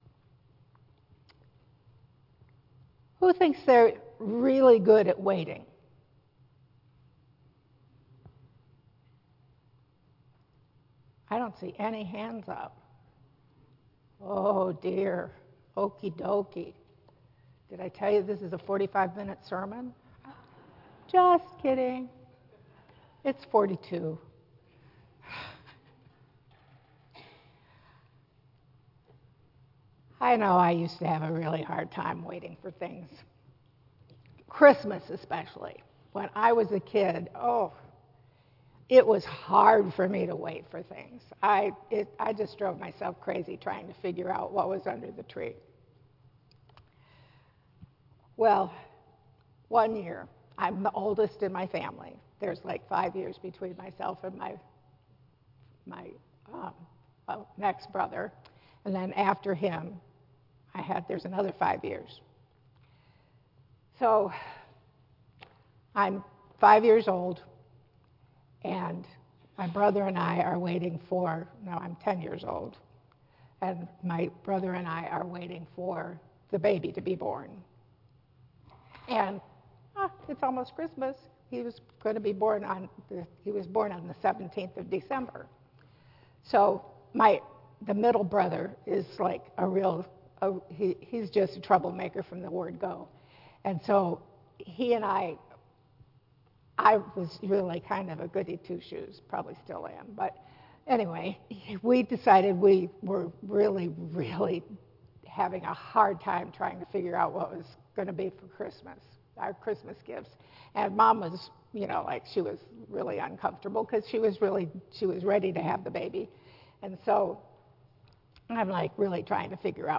Women of the Bible II Message Series Based on Isaiah 41:8-14 and Luke 2:36-38. Tagged with Michigan , Sermon , Waterford Central United Methodist Church , Worship Audio (MP3) 14 MB Previous Sarai (Sarah) - Princess of Laughter Next Delilah